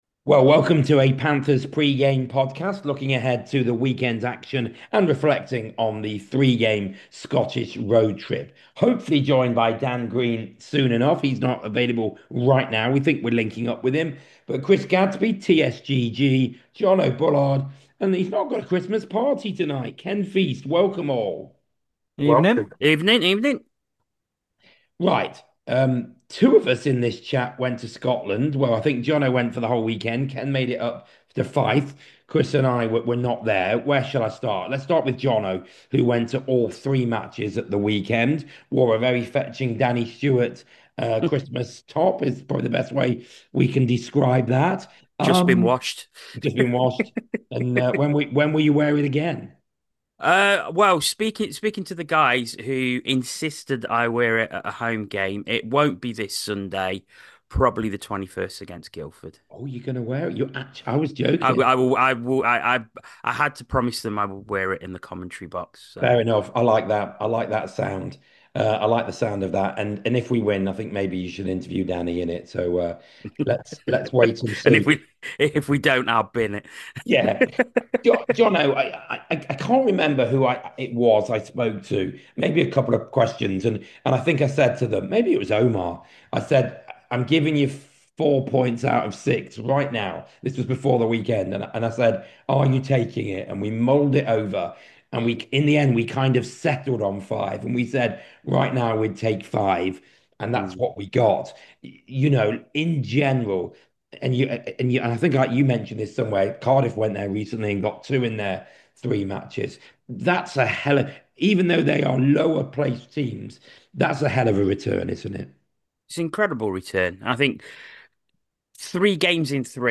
The five guys reflect on last week's three-game Scotland road trip which saw Panthers seucre five points from a possible six, with wins over Glasgow Clan and Fife Flyers as well as an overtime defeat by Dundee Stars. They also discuss the signing of Swedish defenceman Jakob Stridsberg who played for Midlands rivals Coventry Blaze last season.